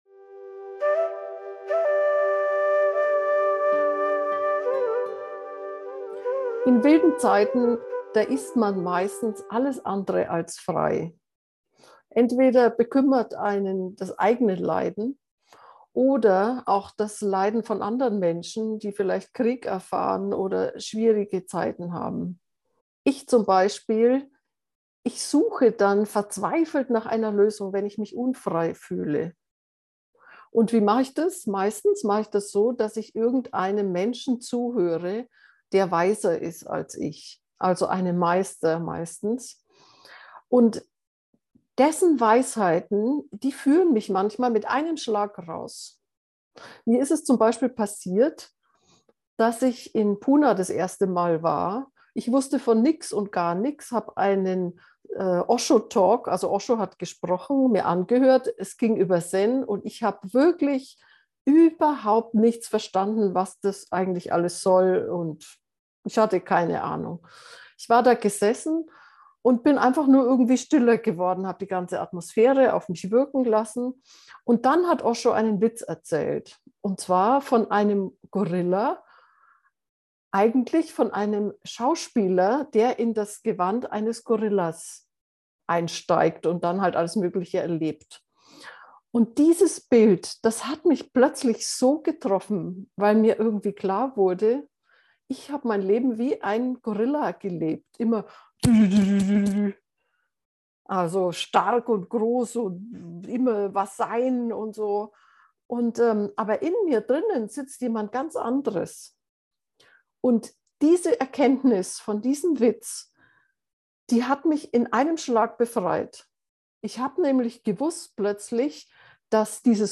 Ich flüstere dir in dieser Meditation einen Satz einer zu, der dich tief berühren und transformieren kann.
Geführte Meditationen